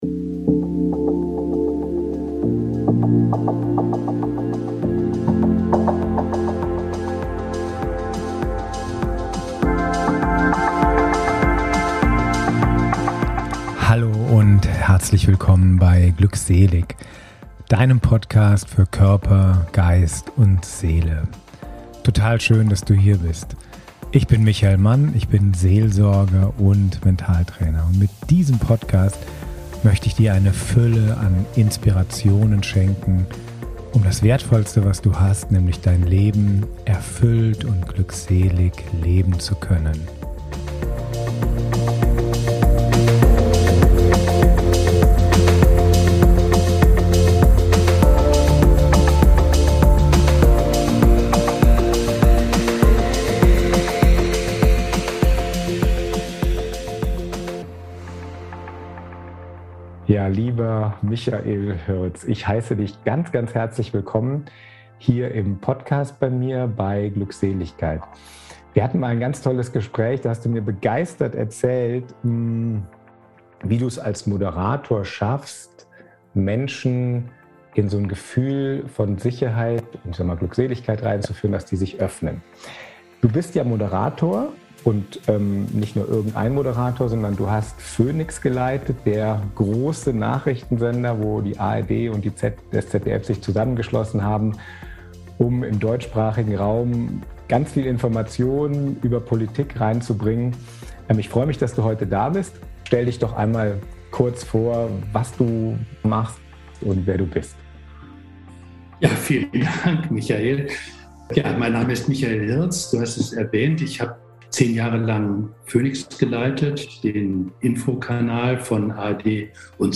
Beschreibung vor 3 Jahren Michael Hirz ist Journalist und Moderator.
Im heutigen Gespräch erzählt er, wie wir mit guter Moderation auch im Alltag Brücken zueinander bauen können. Resigniert blickt er aber auch auf den Dialogprozess der Kirche.